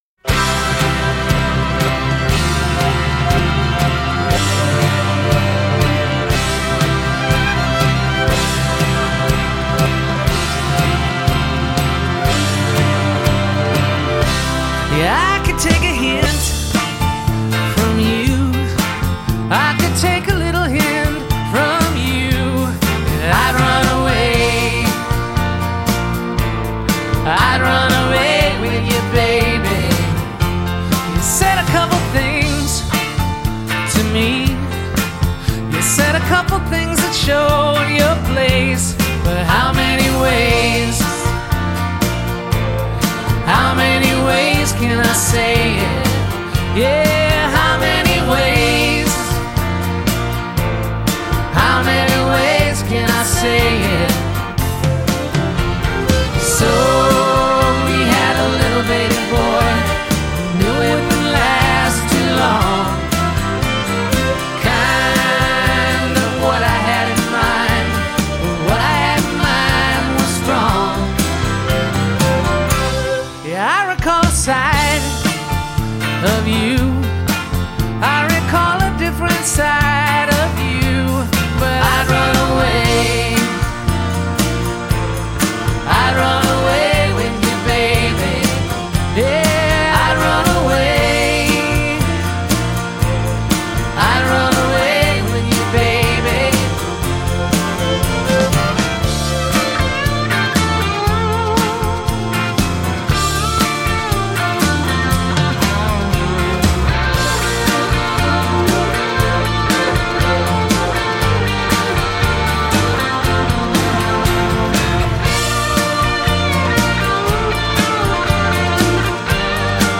a lighter, more pop-leaning sound